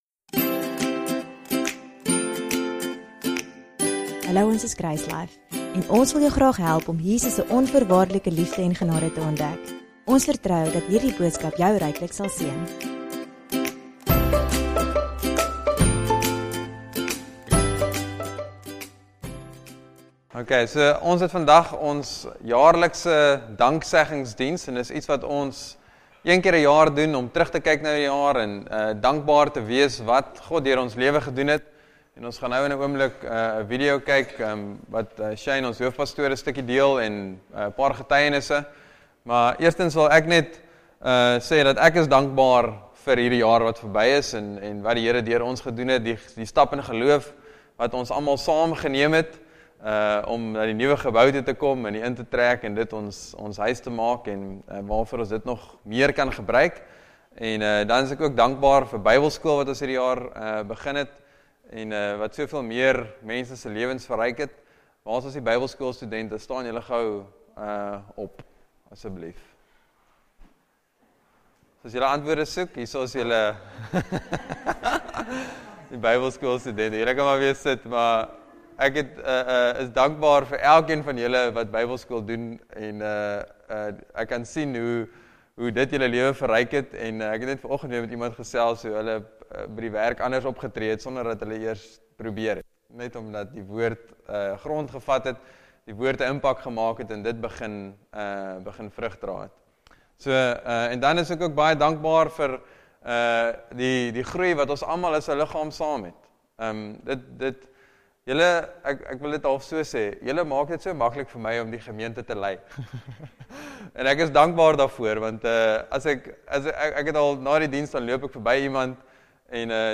Dankseggingsdiens: Laat God Jou Die Weg Aanwys